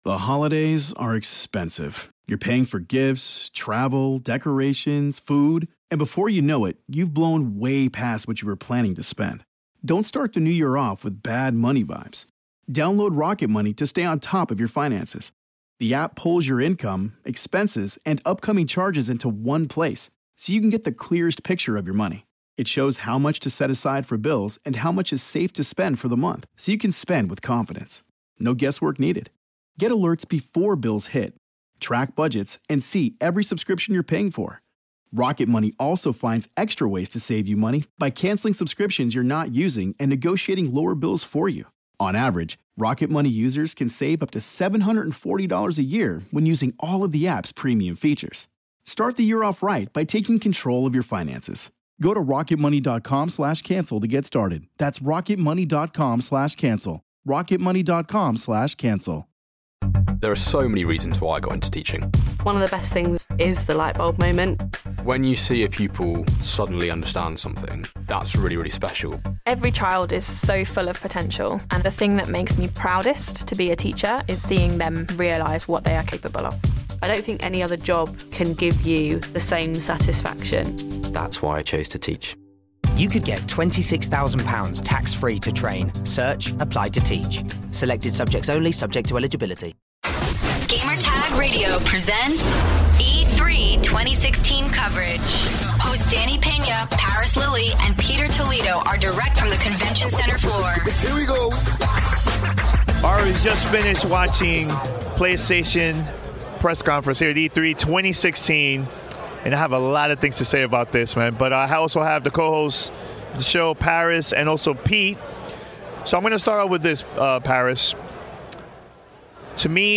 PlayStation press conference roundtable discussion.